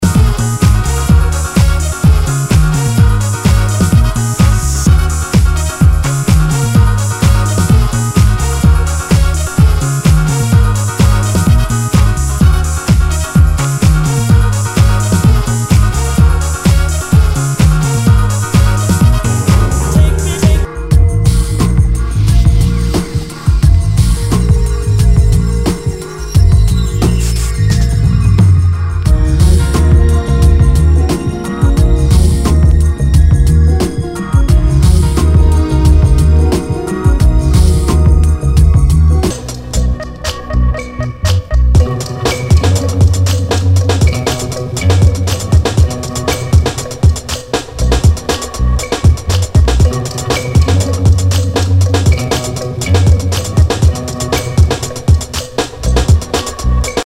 HOUSE/TECHNO/ELECTRO
ディスコ・ハウス / ダウンテンポ / ブレイクビーツ！